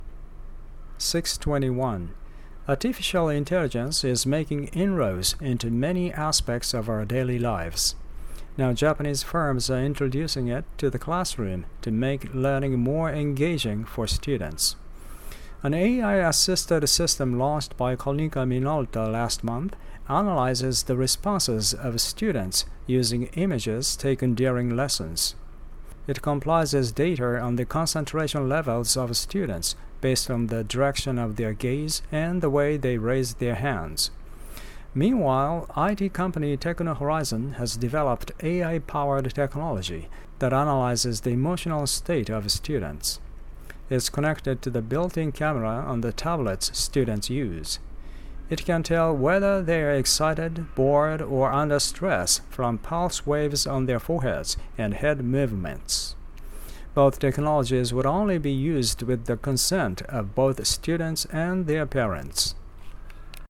（これは著作権の関係で僕が読んでいます）